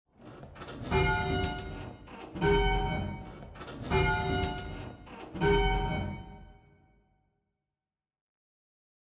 Minor 2nd Intervals
A minor 2nd interval is the smallest gap found in a scale and is found between the 3rd & 4th  and 7th &  8th notes of a major scale.